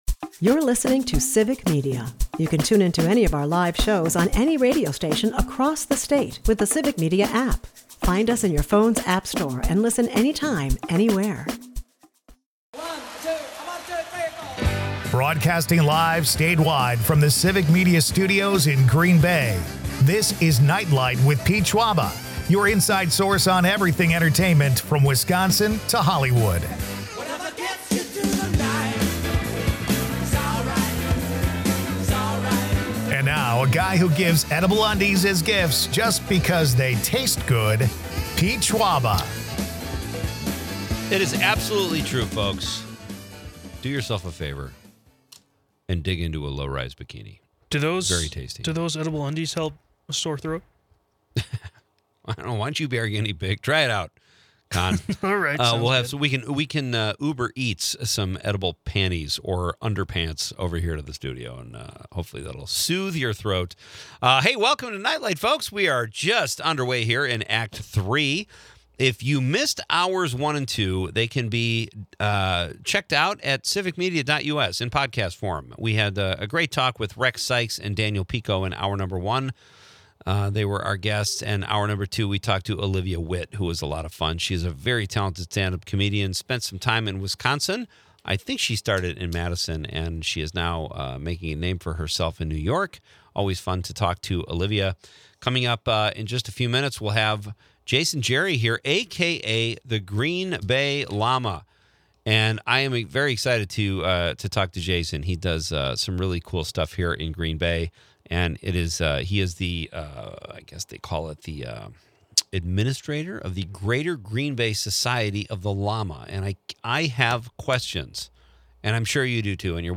Kick things off with the show's lively banter about edible undies and celebrity crushes, where Carrie Coon and Odessa A'zion take the spotlight.